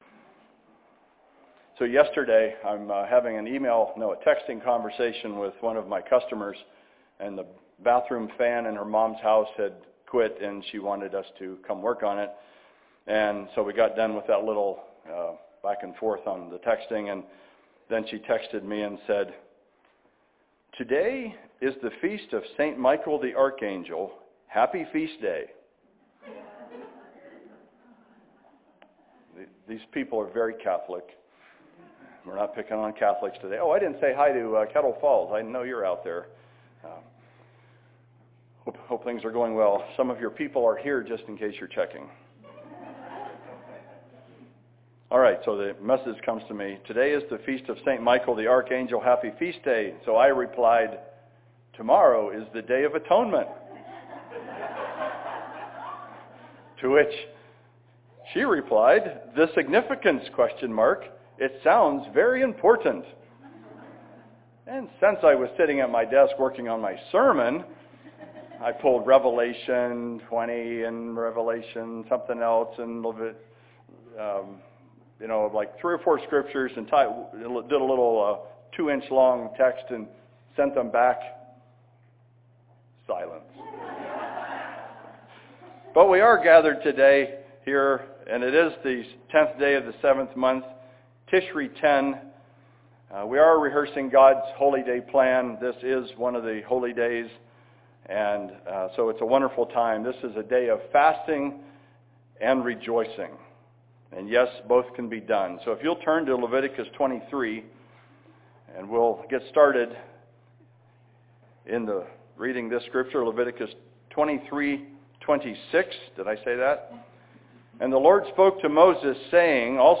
Sermon on Day of Atonement, Saturday, September 30, 2017 in Spokane, Washington.